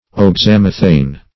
Search Result for " oxamethane" : The Collaborative International Dictionary of English v.0.48: Oxamethane \Ox`a*meth"ane\, n. [Oxamic + ethyl.]
oxamethane.mp3